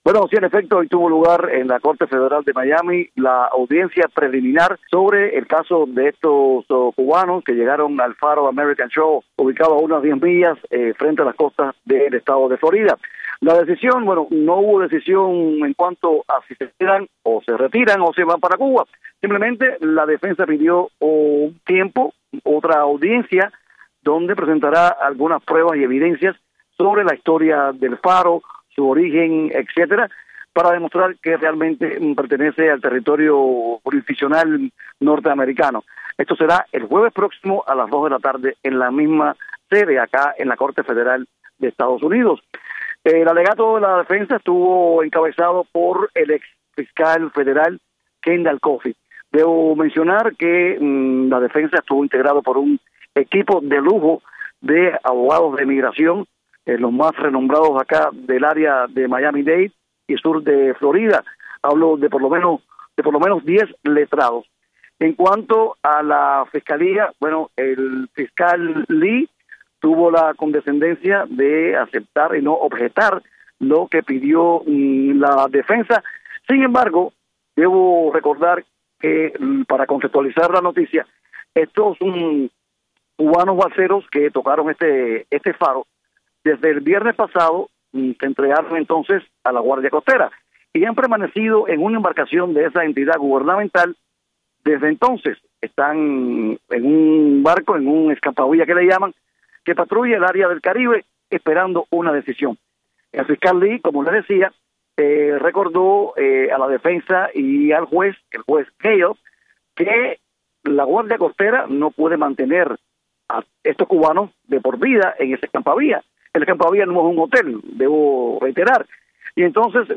Desde la corte federal de Miami